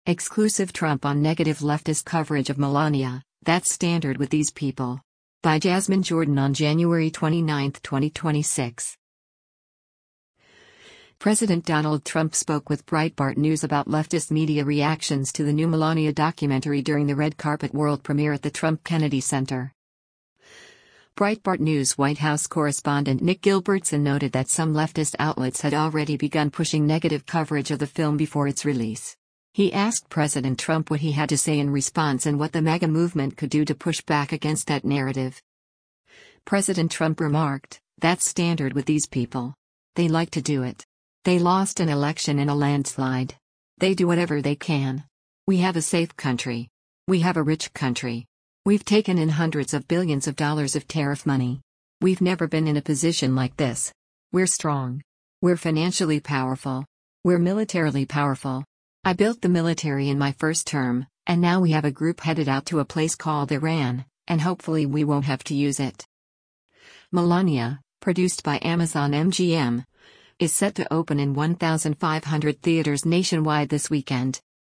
President Donald Trump spoke with Breitbart News about leftist media reactions to the new Melania documentary during the red carpet world premiere at the Trump-Kennedy Center.